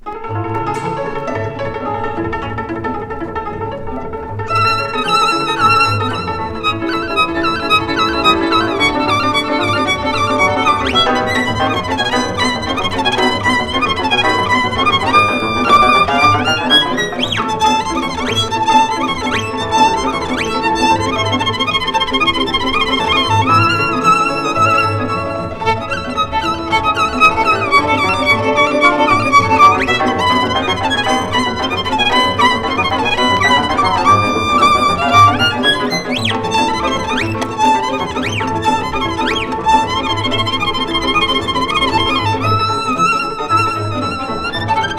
World, Folk, Romani　Hungary　12inchレコード　33rpm　Stereo